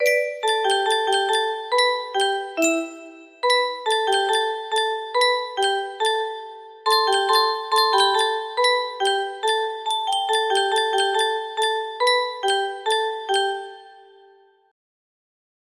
(lava) music box melody